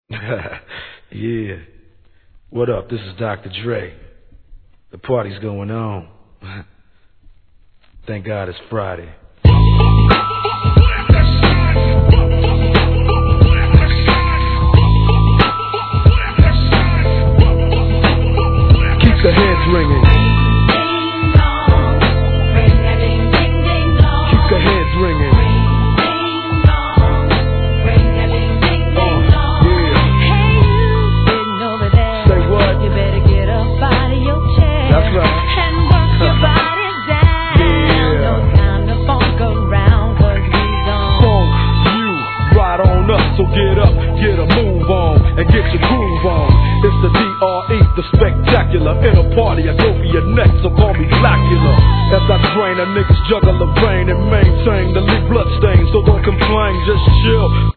G-RAP/WEST COAST/SOUTH
「リンリンド〜♪」のキャッチーなフック!